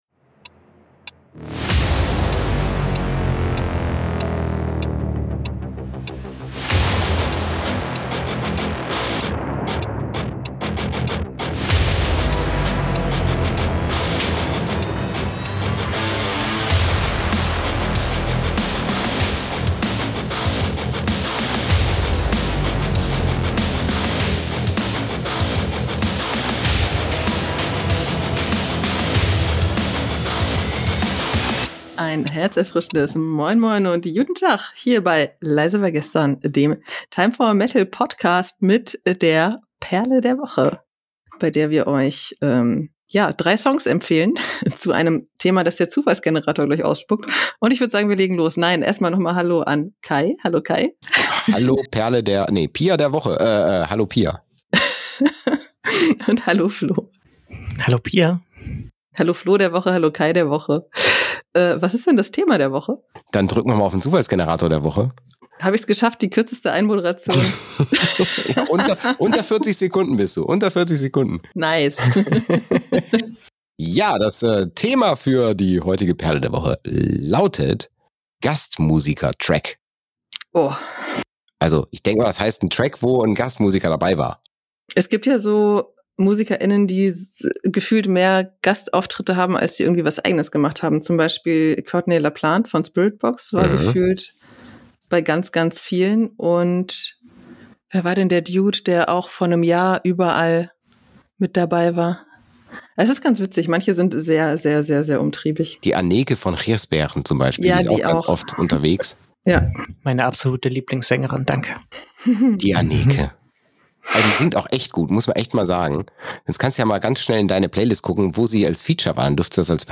Zu Beginn der kurzweiligen Episoden sucht sich der Zufallsgenerator ein Thema raus, welches dann als Basis für jeweils eine Empfehlung der Moderatoren herangezogen wird.